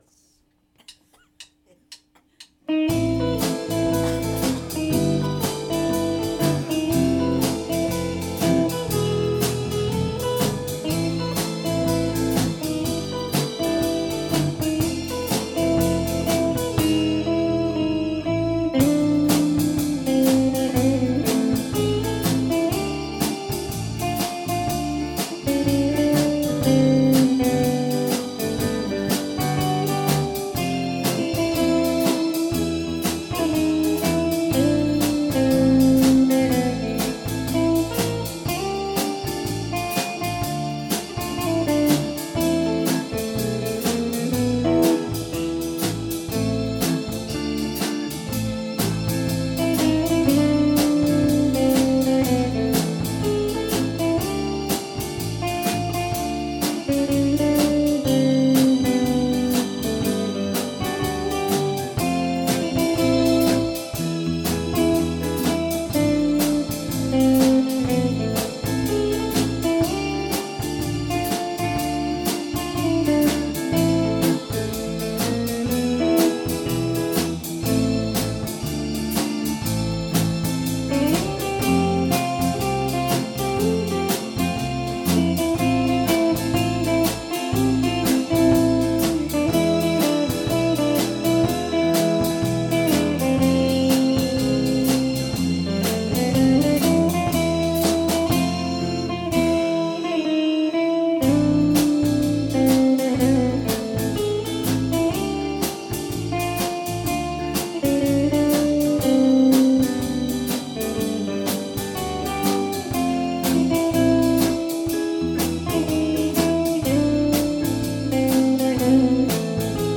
2018 Spring Live-1 | The Arou Can